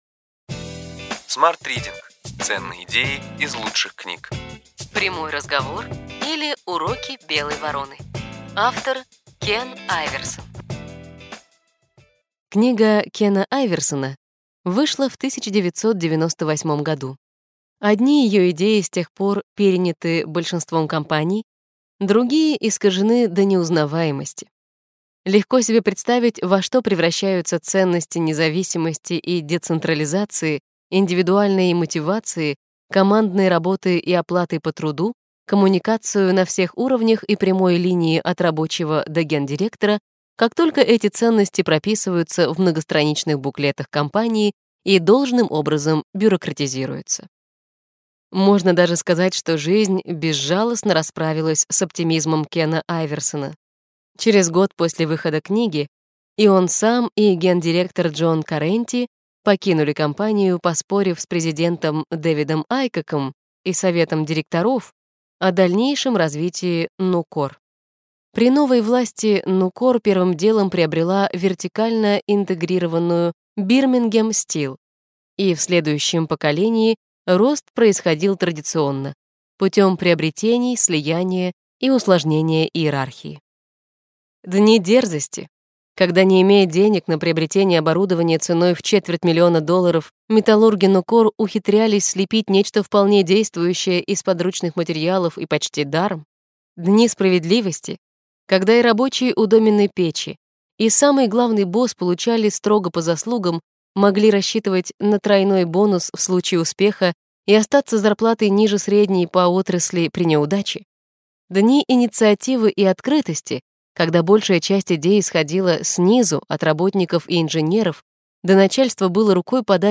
Аудиокнига Ключевые идеи книги: Простой разговор: уроки «белой вороны». Кен Айверсон | Библиотека аудиокниг